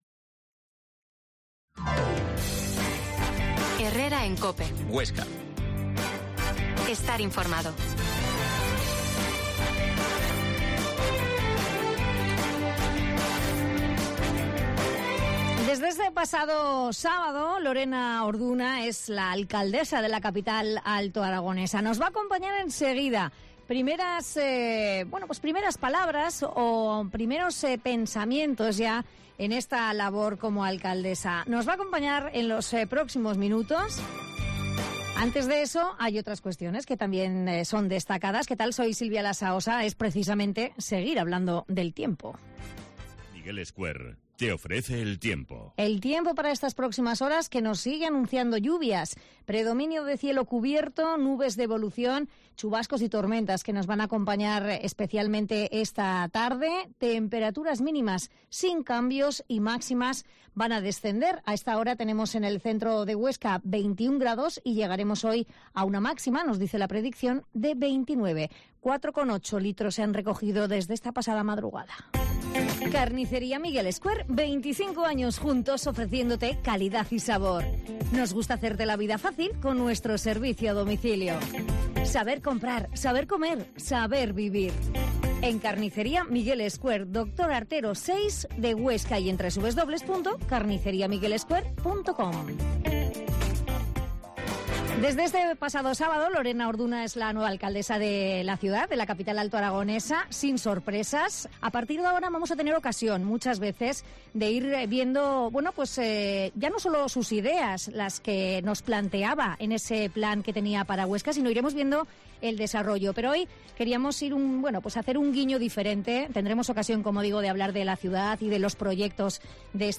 AUDIO: Ultima hora de la actualidad y conocemos con más detalle a la alcaldesa Lorena Orduna en una entrevista personal